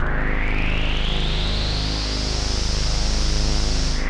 KORG G#1 3.wav